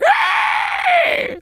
pig_scream_05.wav